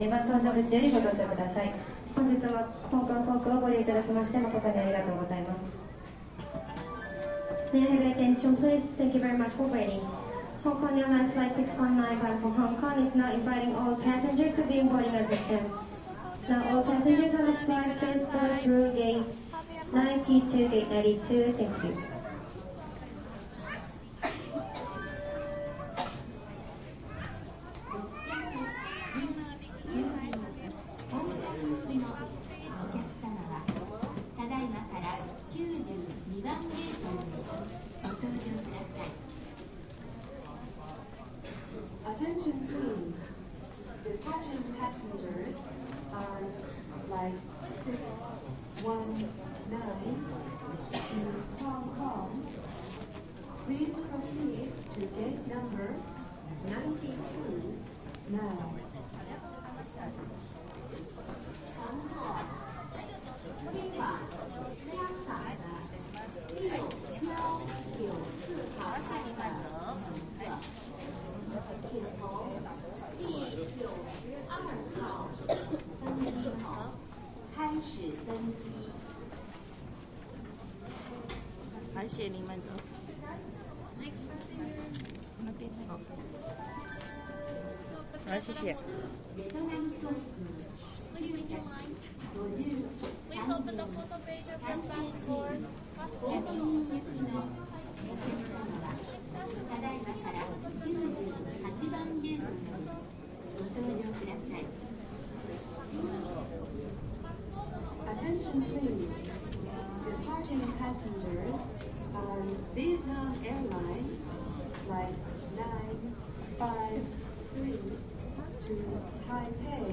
机场 " 釜山机场登机
描述：2011年1月在韩国釜山金海机场录制的。即将登上大韩航空飞往香港的航班。用Zoom H2在2声道环绕模式下录制，没有挡风玻璃。
标签： 韩国 韩语 登机 公告 现场记录 釜山 机场
声道立体声